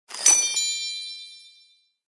SMS Alert
Festive Notification